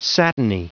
Prononciation du mot satiny en anglais (fichier audio)
Prononciation du mot : satiny